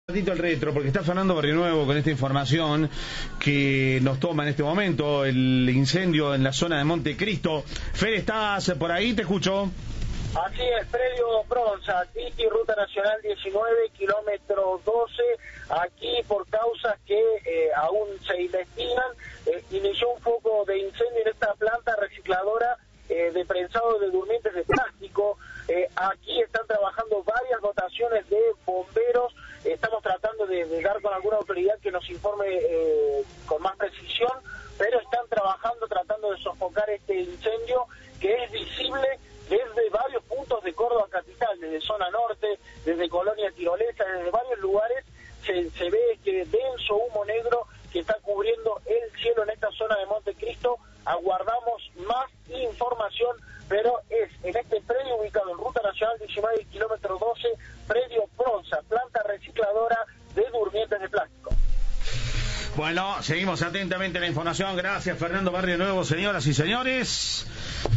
La intendenta de Monte Cristo, Verónica Gazzoni, habló con Cadena 3 y explicó que lo que se prendió fuego la materia prima de la fábrica, que es altamente inflamable.